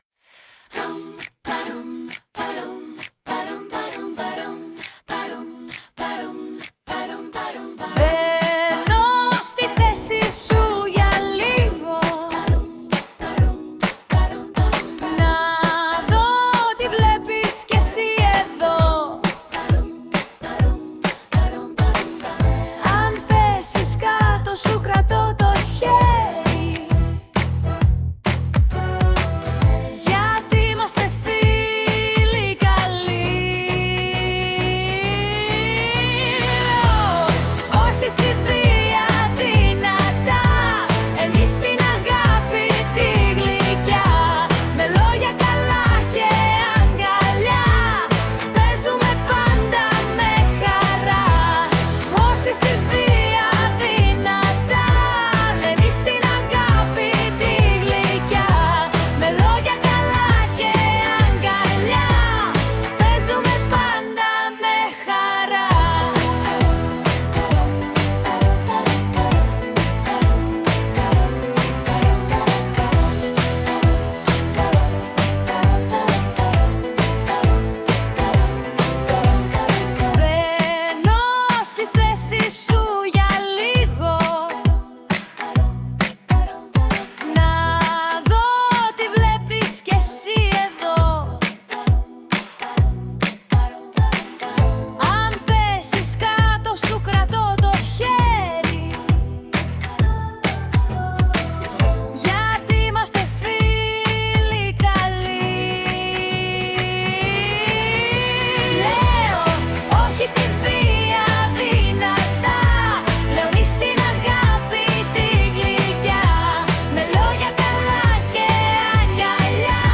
Κάνουν το ποίημα τους τραγούδι μέσω του ψηφιακου εργαλείου Suno